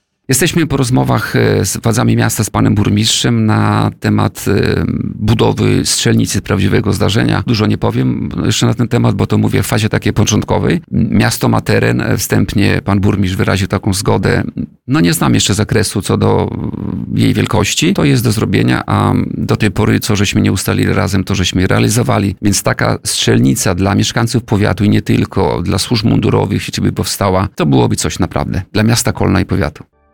Starosta kolneński Tadeusz Klama zdradził na antenie Radia Nadzieja, że niewykluczony jest scenariusz, w którym powiat we współpracy z miastem Kolno wybudują nowoczesną strzelnicę, z której korzystać mogliby mieszkańcy regionu.